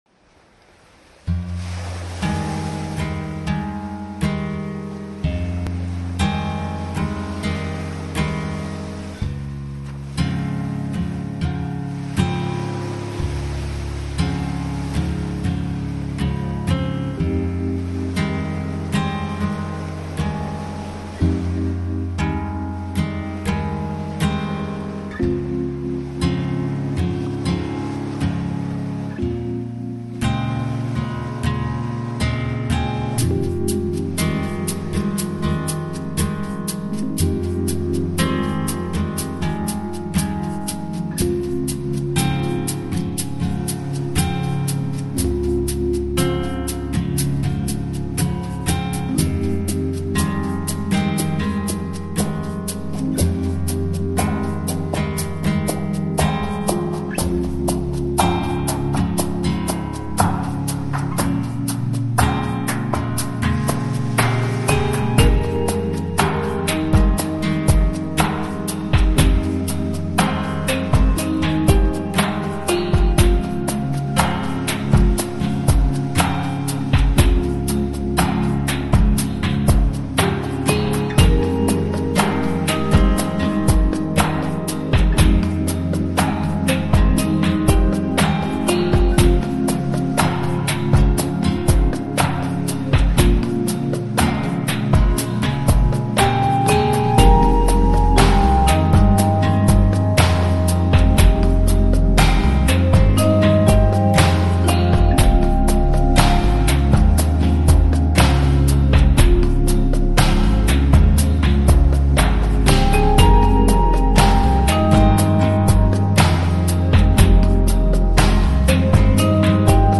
Barber Beats / Vapor / Chillout